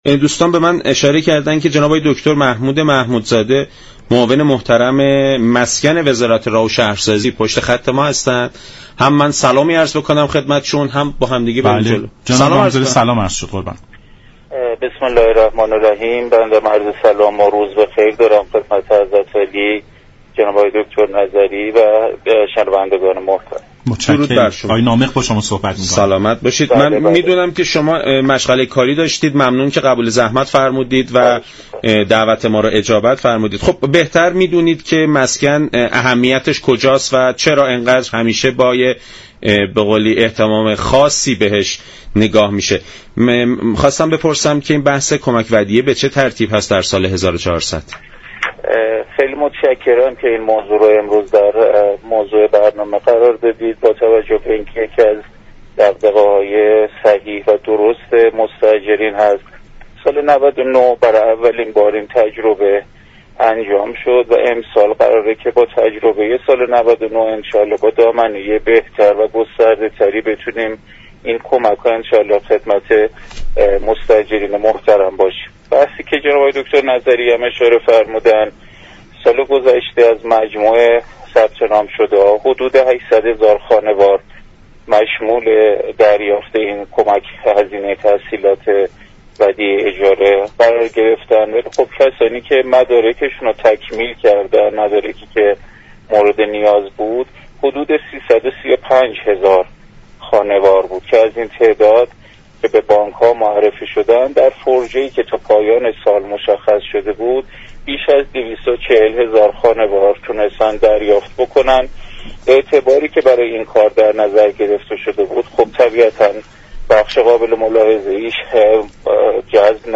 به گزارش شبكه رادیویی ایران، محمود محمودزاده معاون مسكن و ساختمان وزارت راه و شهرسازی در برنامه »سلام صبح بخیر» رادیو ایران به طرح كمك ودیعه مسكن اشاره كرد و گفت: كمك ودیعه مسكن كه سال گذشته با موفقیت انجام شد امسال نیز ادامه خواهد داشت.